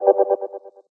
whoosh_long_chopper_3.ogg